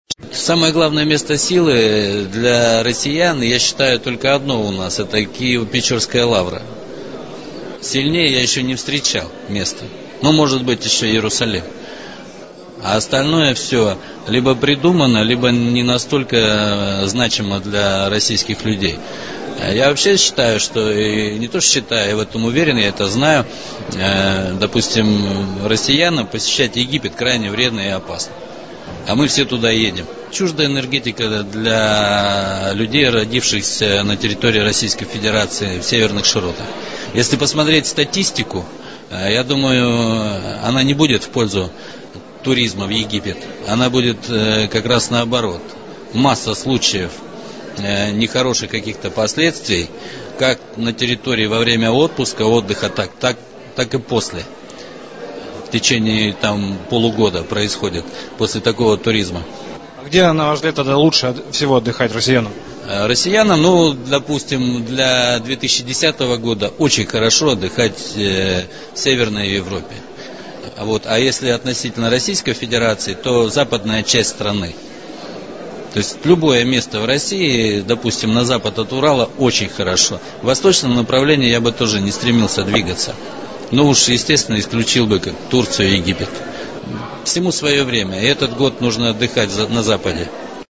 Аудиокнига: Экстрасенсы